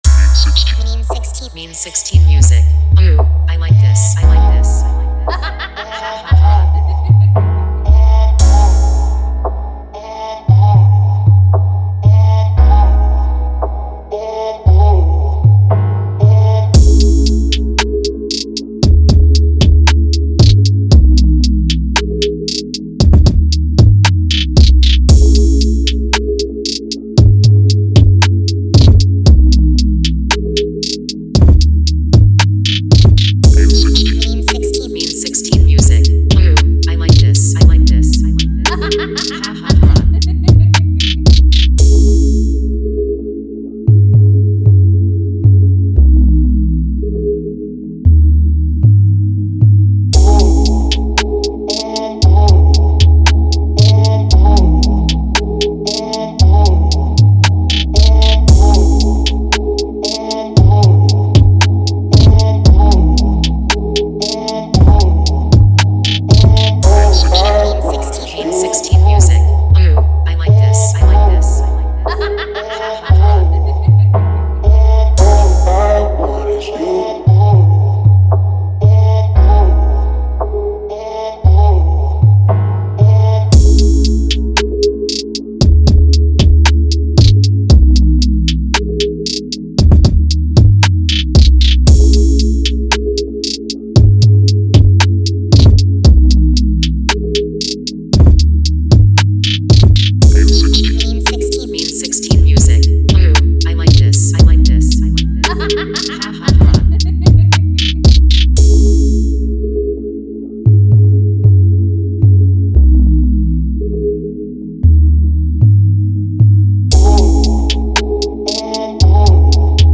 High quality beats made by skilled producers.